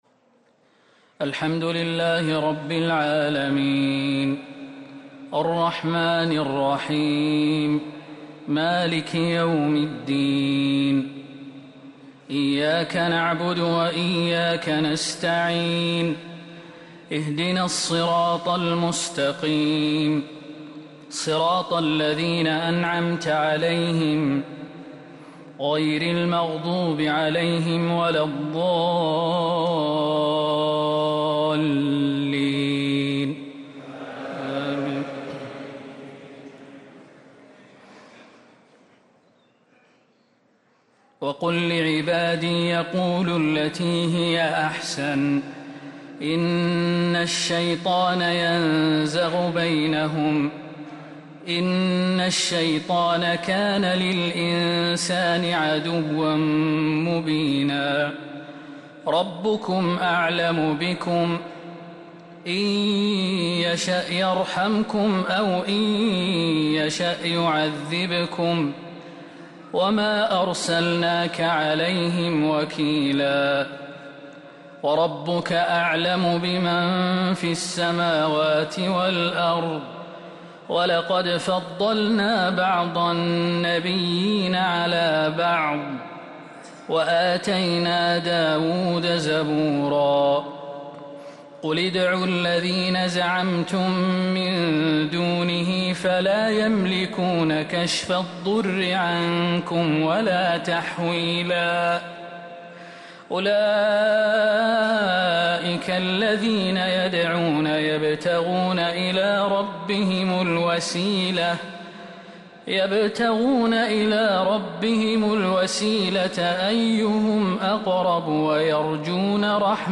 تراويح ليلة 20 رمضان 1444هـ من سورتي الإسراء (53-111) و الكهف (1-26) | Taraweeh 20th night Ramadan1444H Surah Al-Israa and Al-Kahf > تراويح الحرم النبوي عام 1444 🕌 > التراويح - تلاوات الحرمين